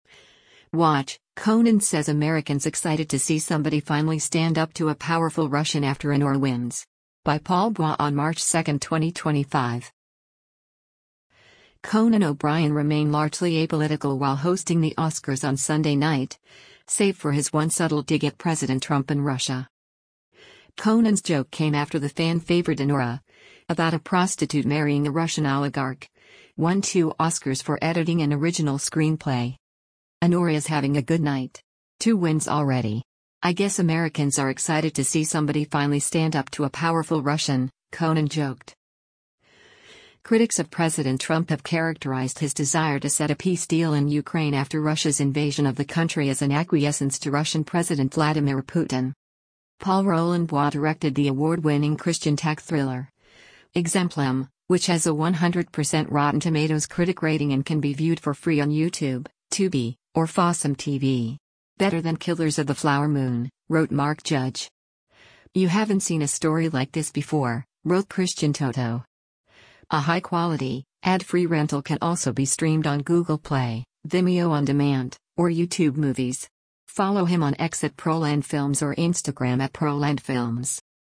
HOLLYWOOD, CALIFORNIA - MARCH 02: Host Conan O'Brien speaks onstage during the 97th A
Conan O’Brien remained largely apolitical while hosting the Oscars on Sunday night, save for his one subtle dig at President Trump and Russia.
“Anora is having a good night. Two wins already. I guess Americans are excited to see somebody finally stand up to a powerful Russian,” Conan joked.